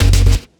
Key-bass_11.1.1.wav